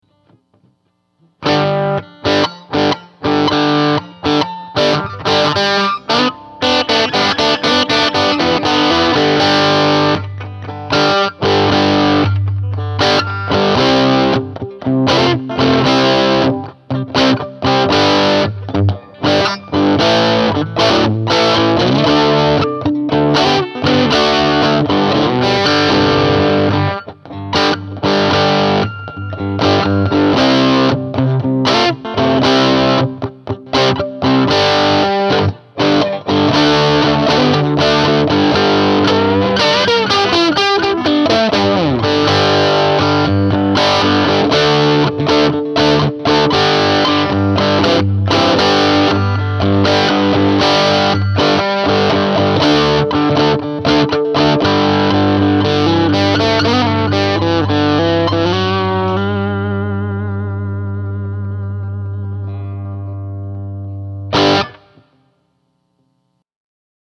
All clips were recorded using my American Deluxe Strat, straight into my VHT Special 6 combo where the speaker resides. Note that I close-miked the amp and had it cranked!
What you hear in these clips is pretty much the raw sound with just a touch of reverb with the dirty clips (<10% wet).
Dirty (Bridge pickup)